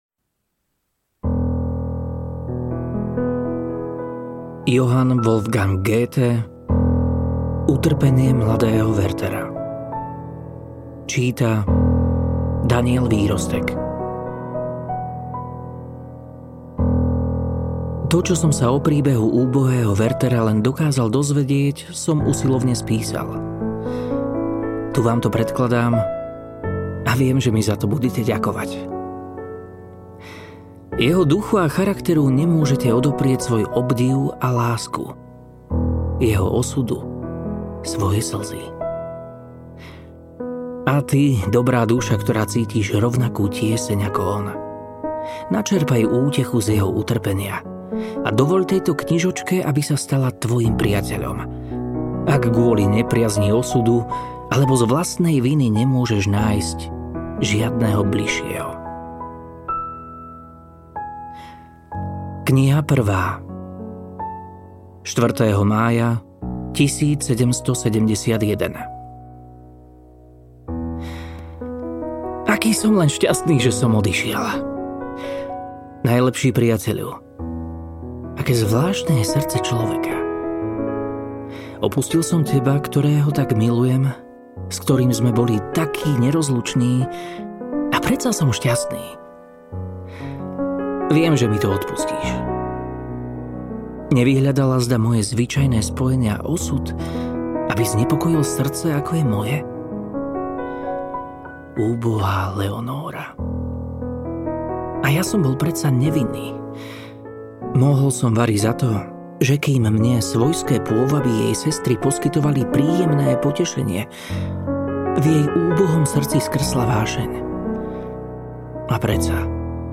Utrpenie mladého Werthera audiokniha
Ukázka z knihy
utrpenie-mladeho-werthera-audiokniha